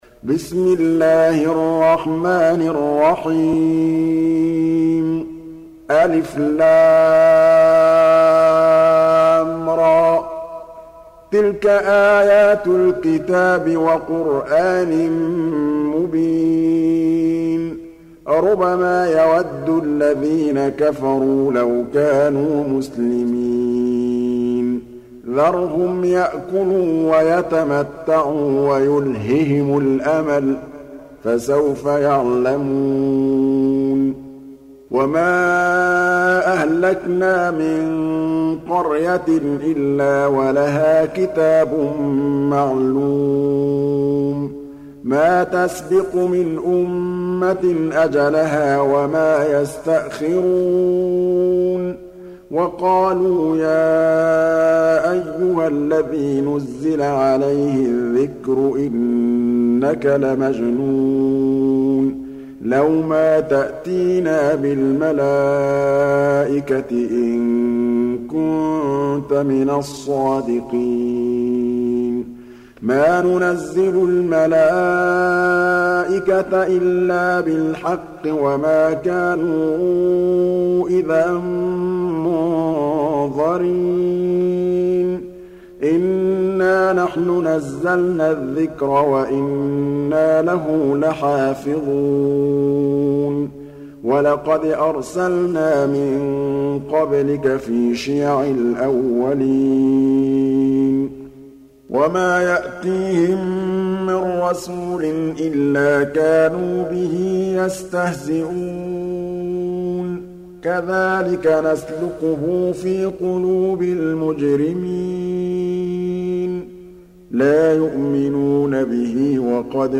15. Surah Al-Hijr سورة الحجر Audio Quran Tarteel Recitation
Surah Repeating تكرار السورة Download Surah حمّل السورة Reciting Murattalah Audio for 15.